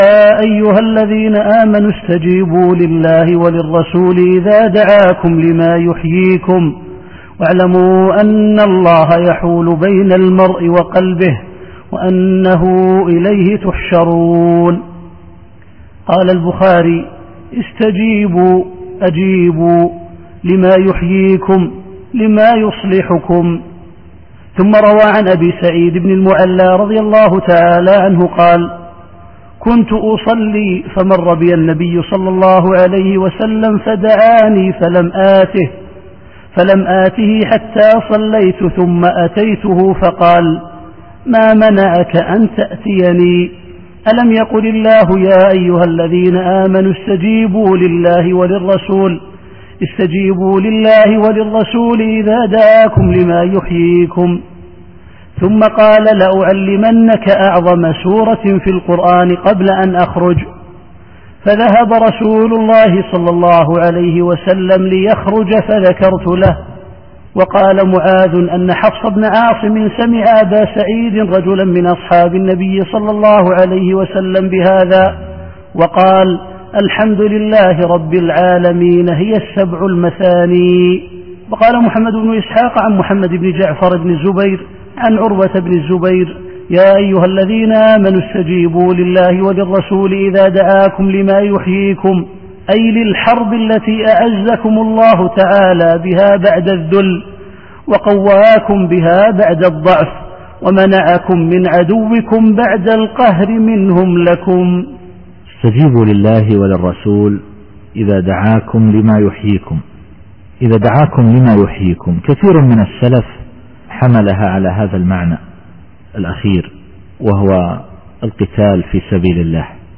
التفسير الصوتي [الأنفال / 24]